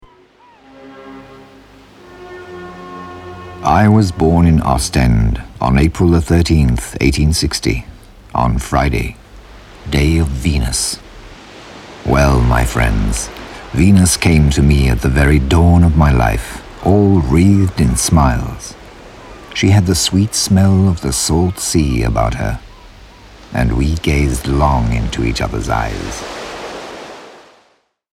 Well, I'd say it's deep, warm, persuasive, with sincerity and authority - but why not have a listen and judge for yourself?
Sprecher englisch / britisch.
Sprechprobe: eLearning (Muttersprache):